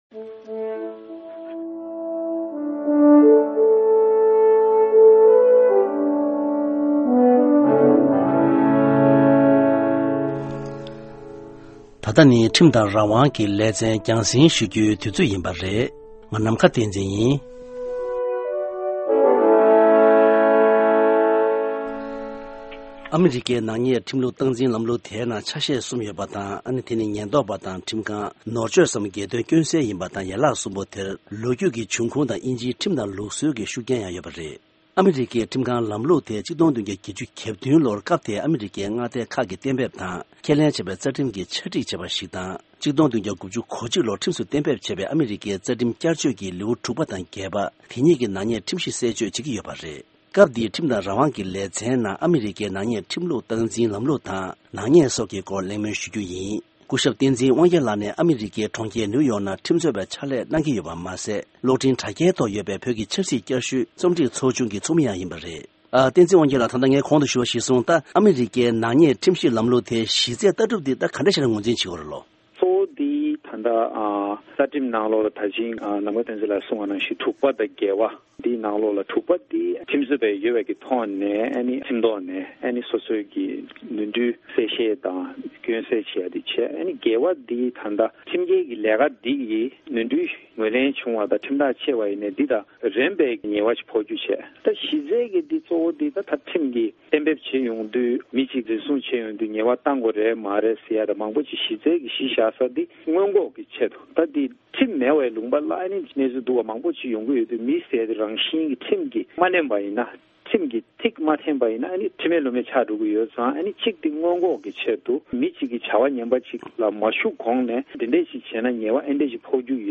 གླེང་མོལ་ཞུས་པ་ཞིག་གསར་རོགས་གནང་།